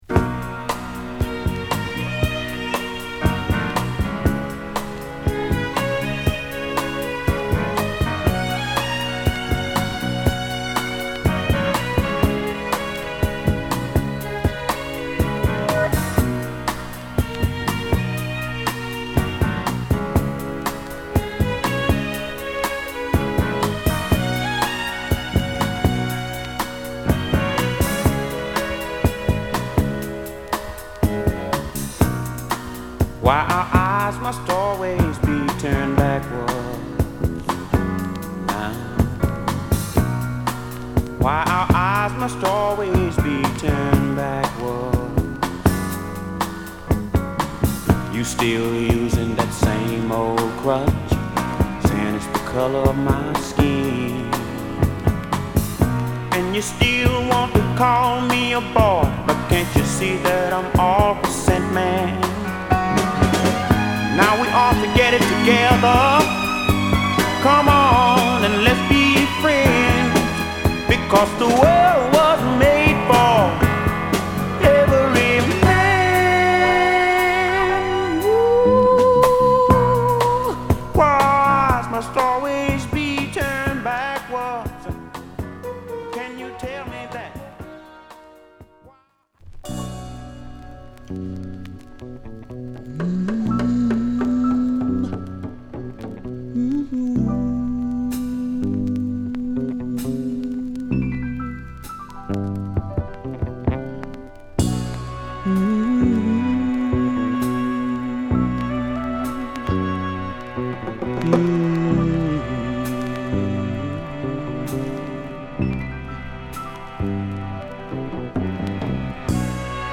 なんとも味わい深いフォーキーソウル名盤
メンフィスソウルの面々がバックを担当する中、独特の郷愁感が沁みるヴォーカルを披露。リムショットが小気味良い